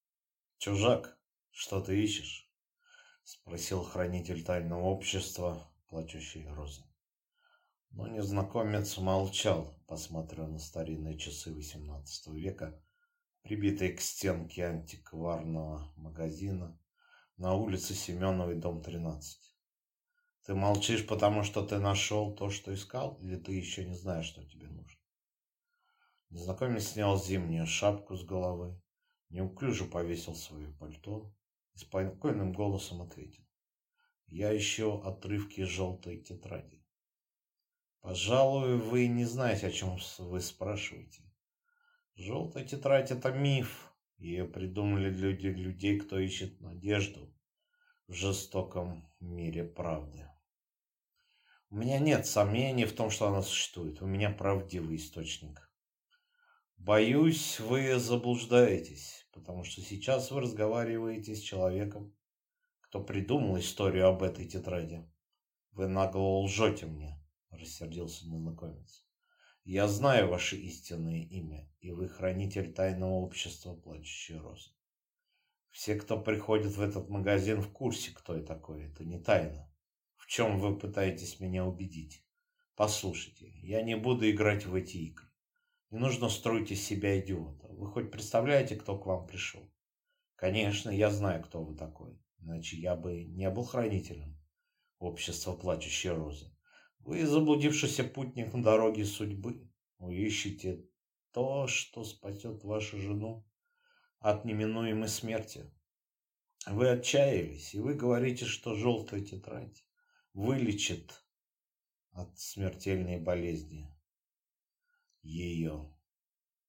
Aудиокнига Бунт плачущих роз